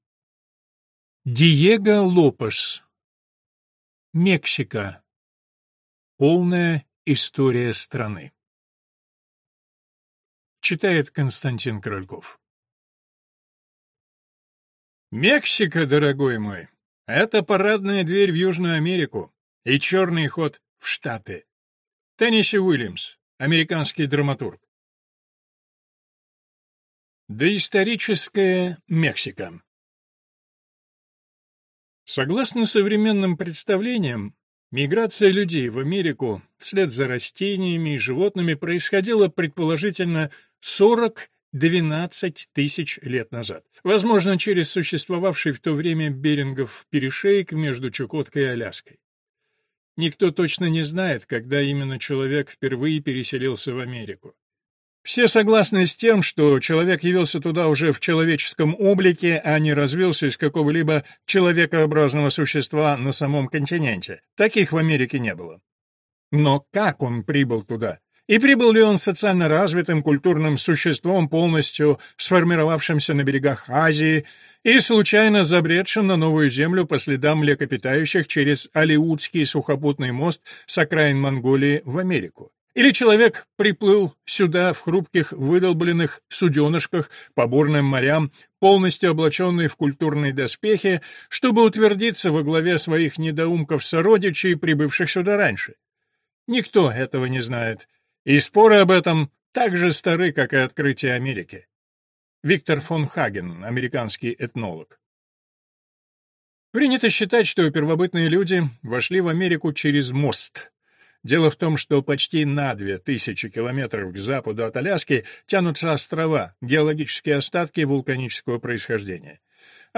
Аудиокнига Мексика. Полная история страны | Библиотека аудиокниг